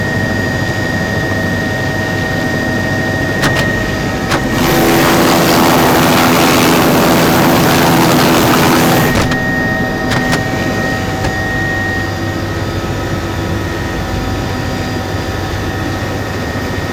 transport
Helicopter Int Open Door In Flight 3